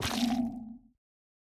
Minecraft Version Minecraft Version latest Latest Release | Latest Snapshot latest / assets / minecraft / sounds / block / sculk / place5.ogg Compare With Compare With Latest Release | Latest Snapshot